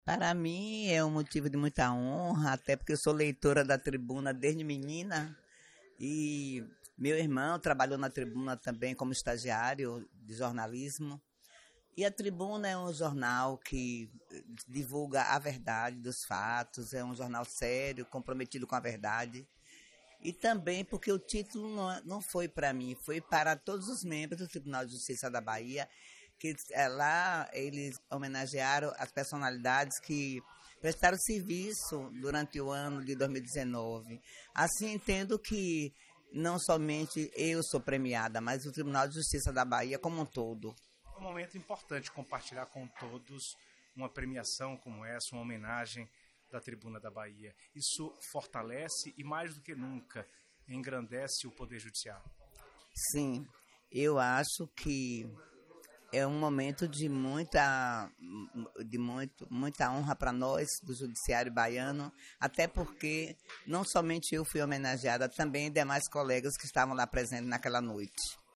A solenidade ocorreu no Casarão Fróes da Mota, na cidade de Feira de Santana, no dia 12/12, e marcou o encerramento das comemorações pelos 50 anos do Jornal Tribuna da Bahia.